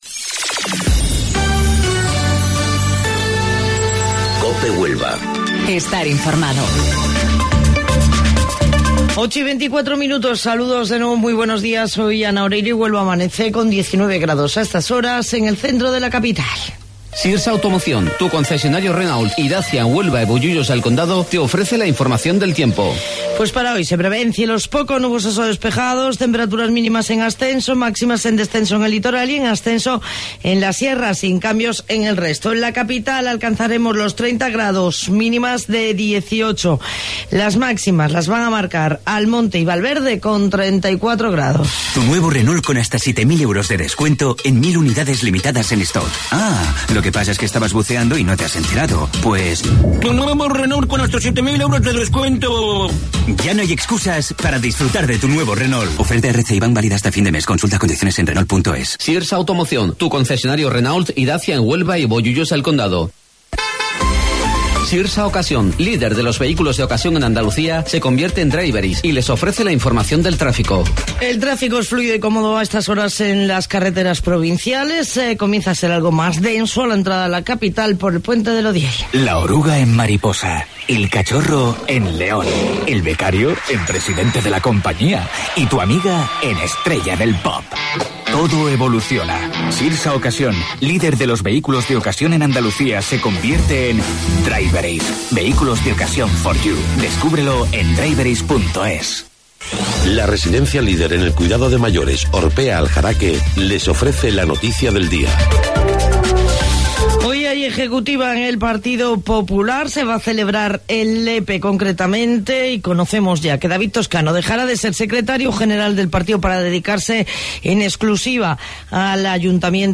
AUDIO: Informativo Local 08:25 del 31 de Julio